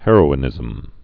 (hĕrō-ĭ-nĭzəm)